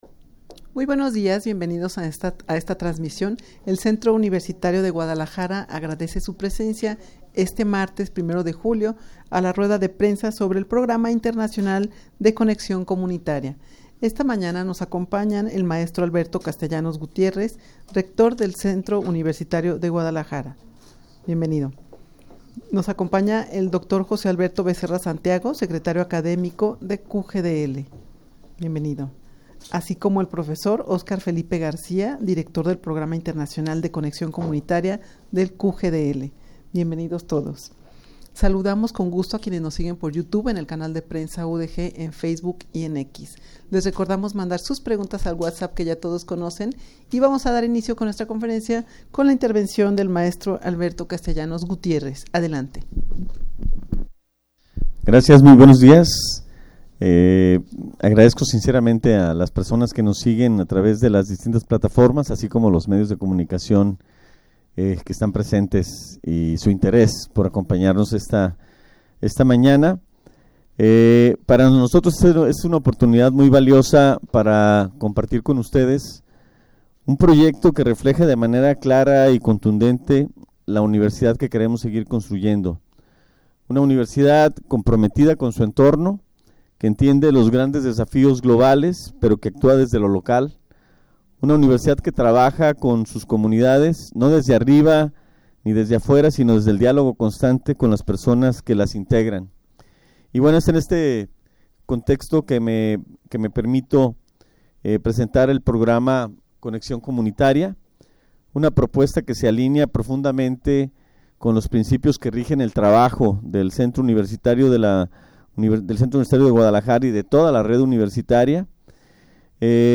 Audio de la Rueda de Prensa
rueda-de-prensa-sobre-el-programa-internacional-de-conexion-comunitaria.mp3